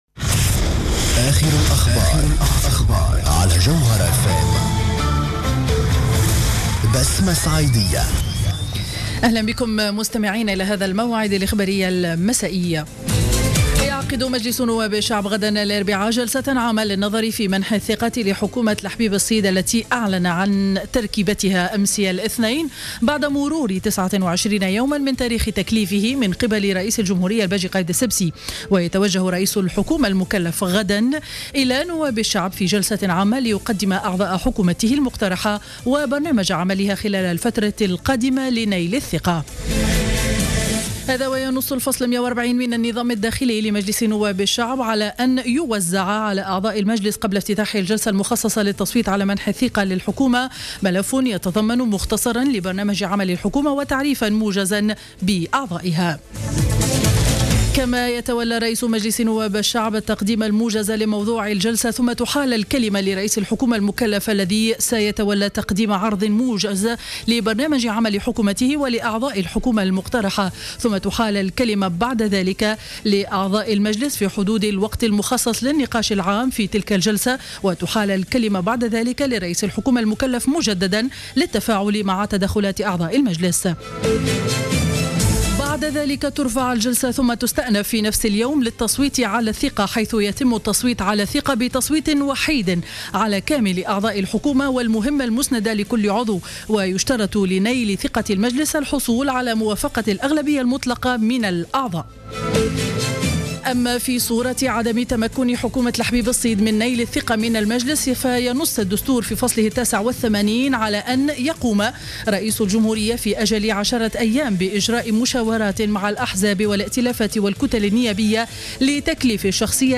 نشرة أخبار السابعة مساء ليوم الثلاثاء 03-02-15